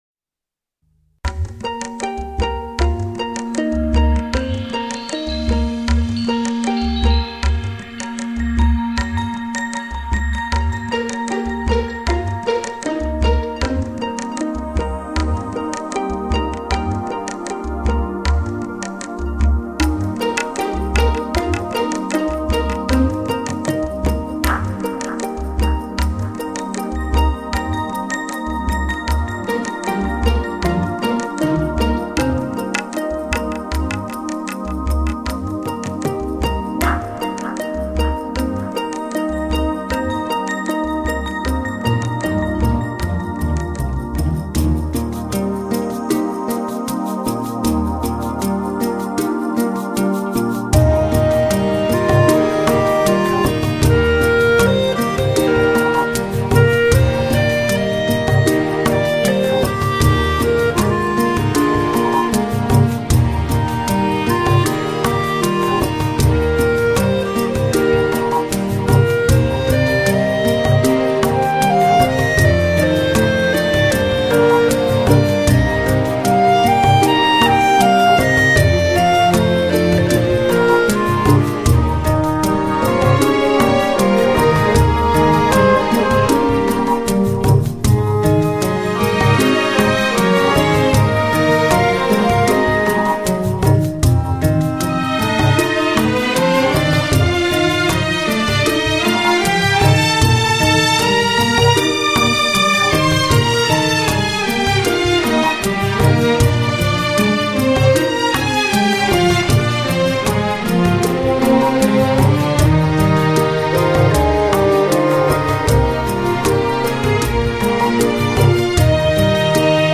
在保留古典乐曲的优雅同时，加入了清爽畅快的节奏，别有一番风味。